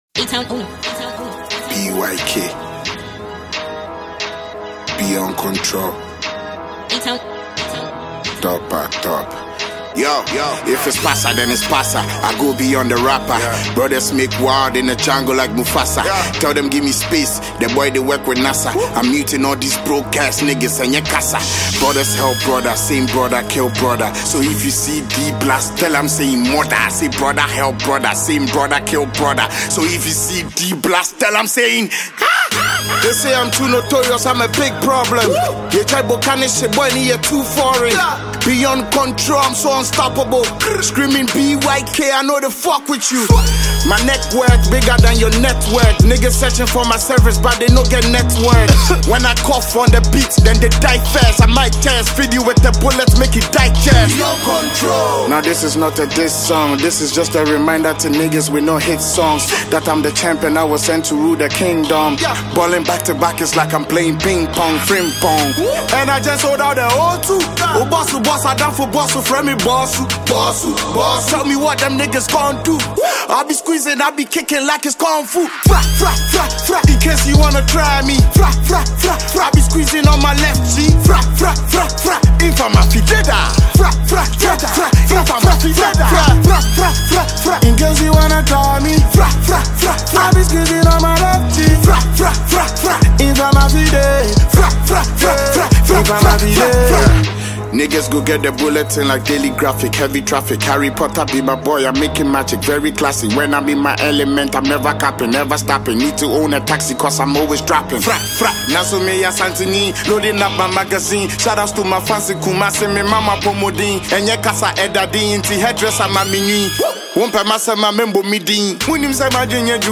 an award-winning Ghanaian rapper